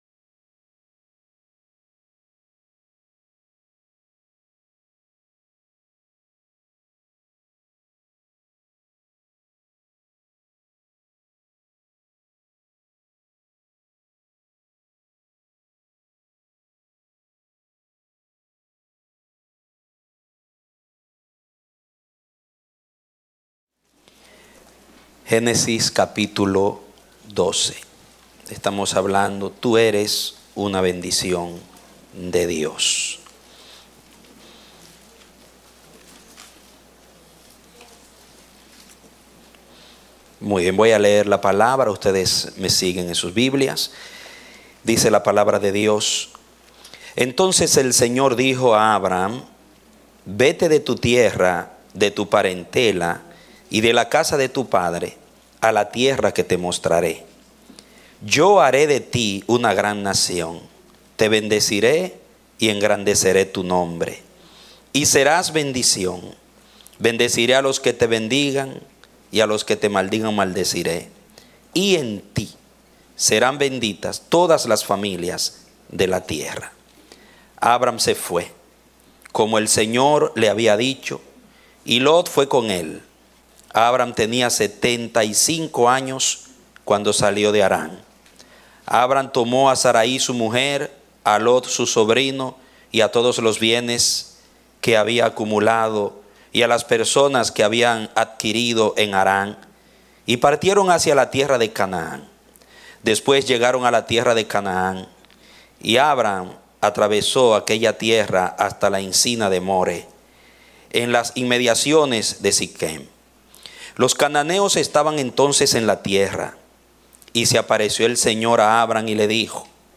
A mensaje from the serie "Eres una Bendición." Predicado Domingo 4 de Septiembre, 2016